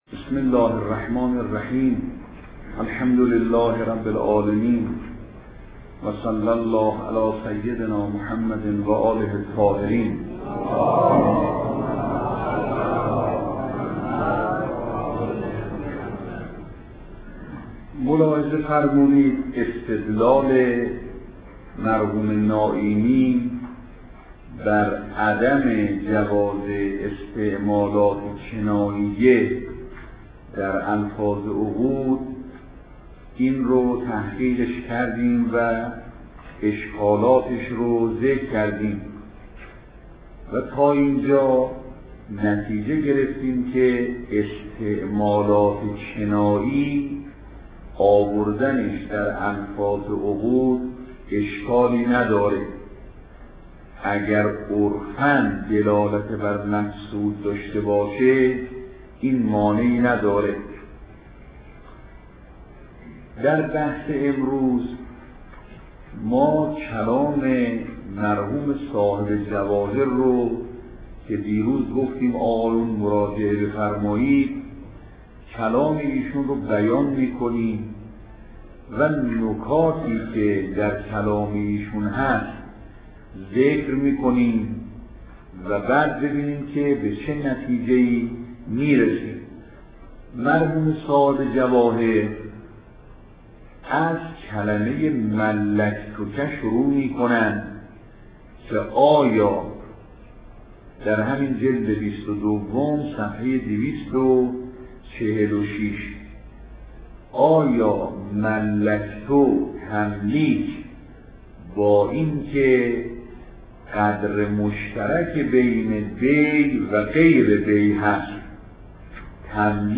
فقه خارج